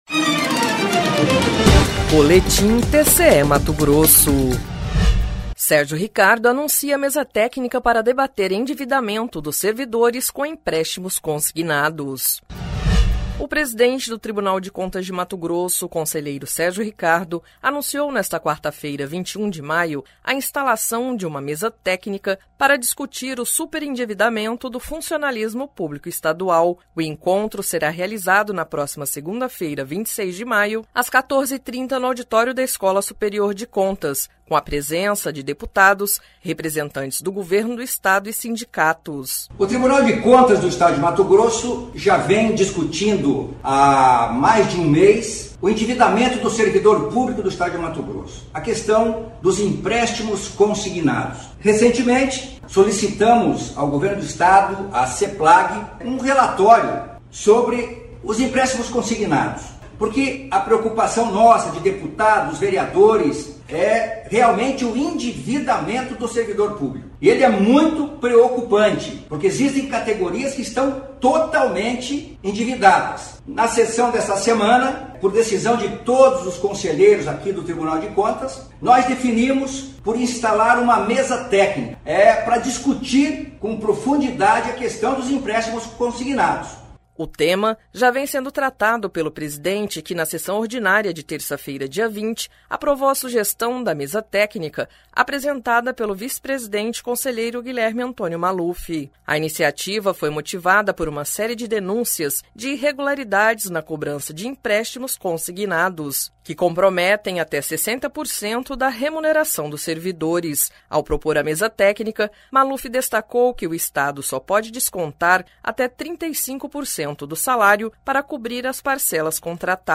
Sonora: Sérgio Ricardo – conselheiro-presidente do TCE-MT
Sonora: Guilherme Antonio Maluf - conselheiro do TCE-MT
Sonora: Waldir Teis - conselheiro do TCE-MT
Sonora: Valter Albano - conselheiro do TCE-MT